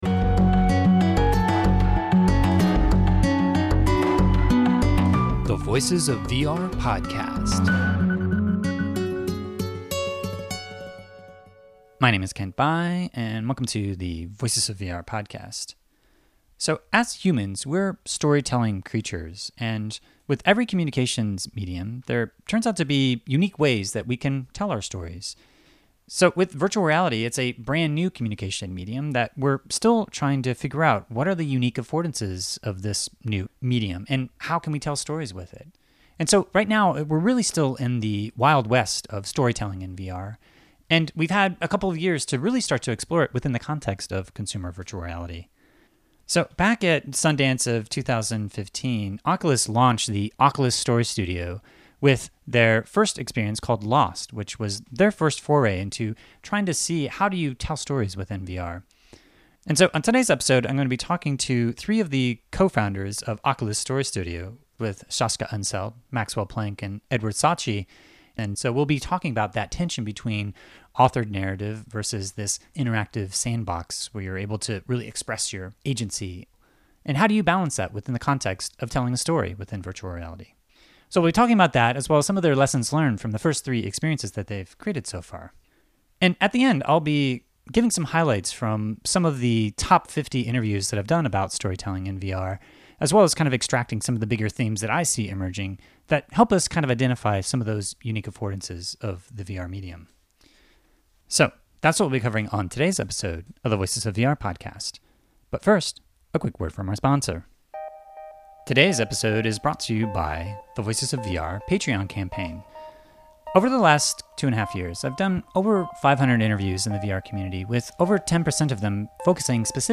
#477: Top 50 VR Storytelling Interviews + Oculus Story Studio Roundtable Discussion – Voices of VR Podcast